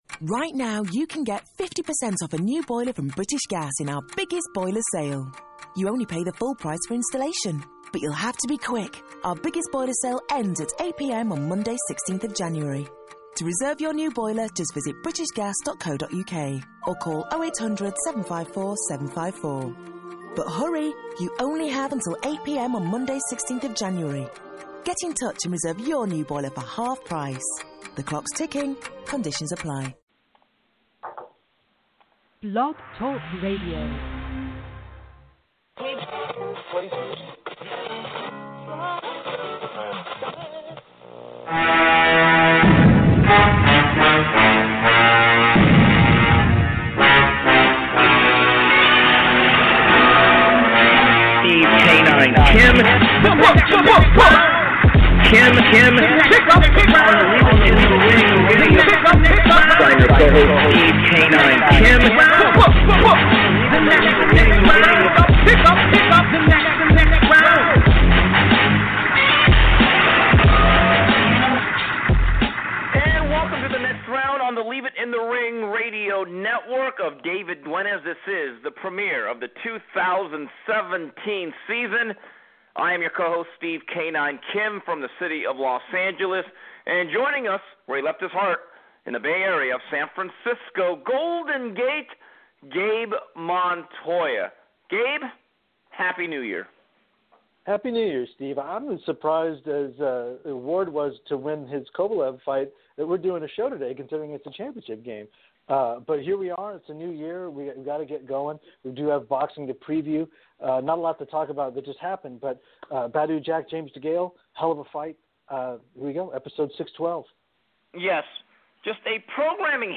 Plus, News & Notes and questions from callers and Twitter.